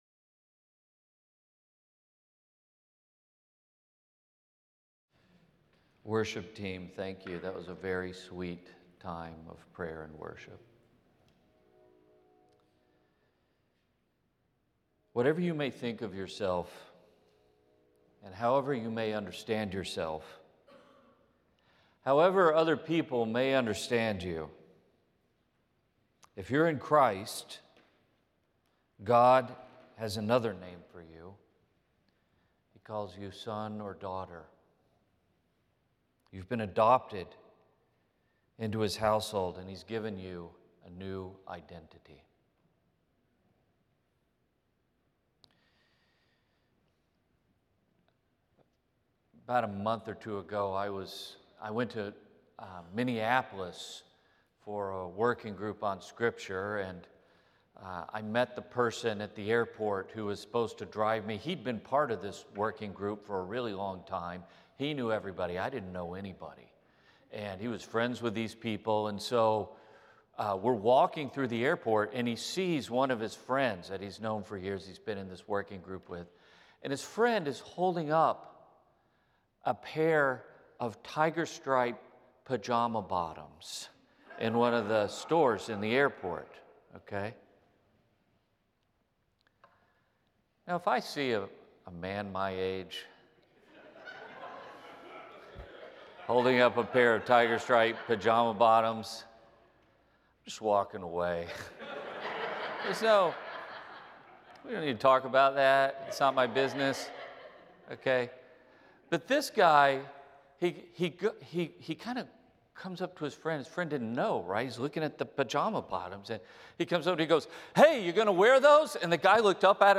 The following service took place on Tuesday, February 17, 2026.